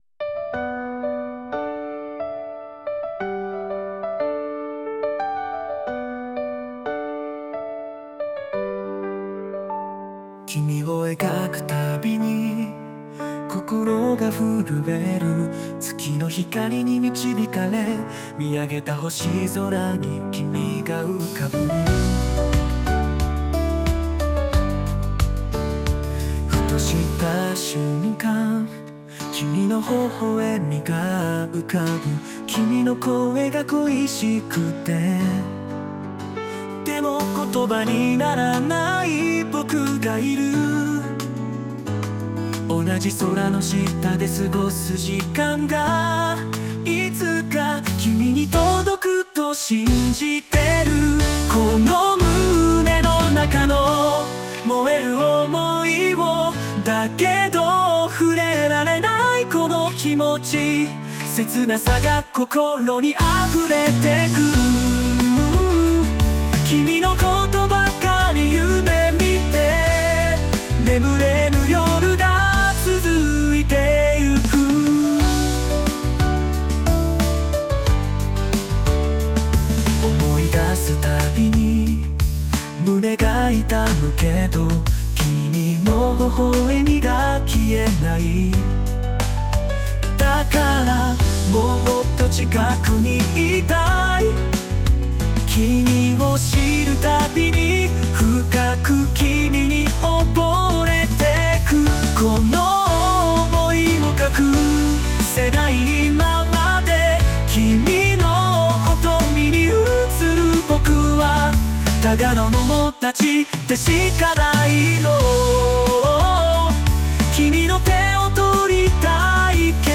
邦楽男性ボーカル著作権フリーBGM ボーカル
男性ボーカル邦楽 男性ボーカル